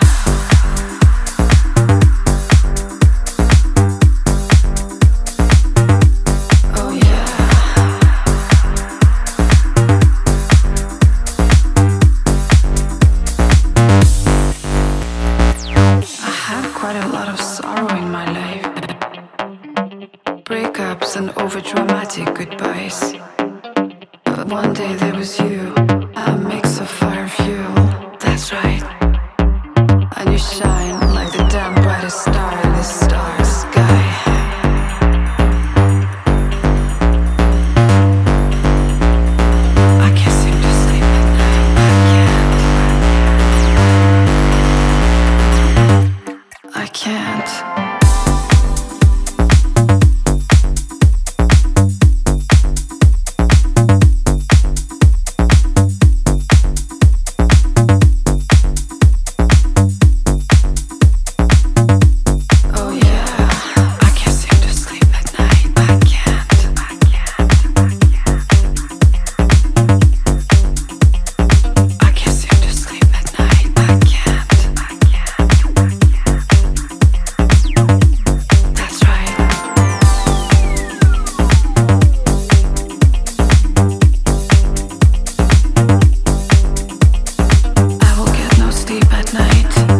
(B面)は各曲ともにソウルフル＆ディスコ〜ディープ・ハウスで捨曲一切無しです。
ジャンル(スタイル) DISCO / HOUSE